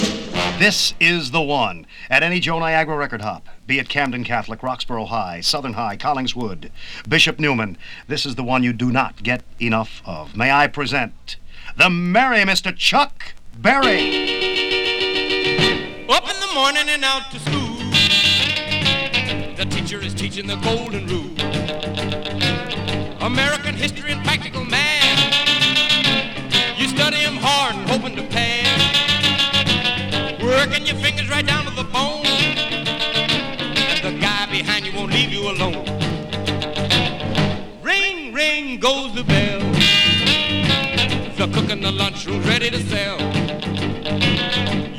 ※Mono音源をStereoにしています。
Rock, Pop, Rock & Roll　USA　12inchレコード　33rpm　Stereo